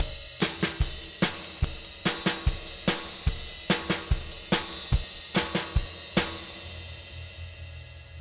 Twisting 2Nite 6 Drumz.wav